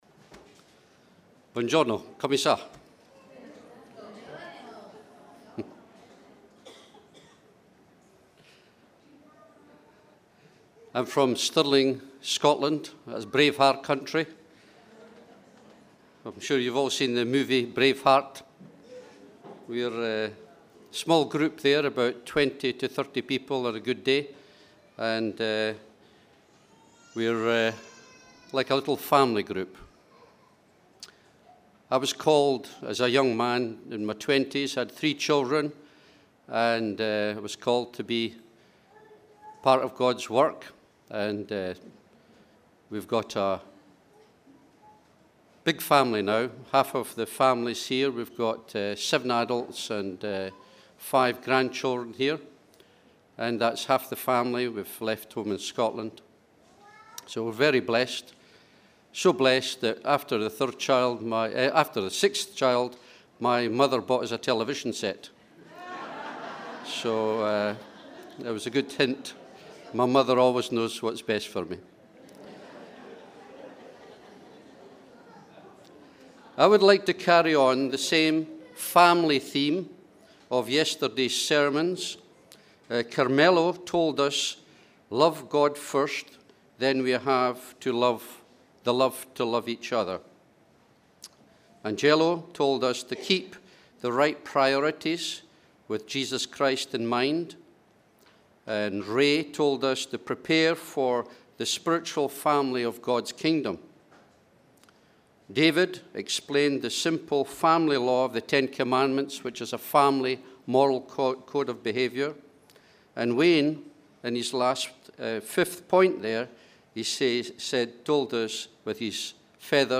English Message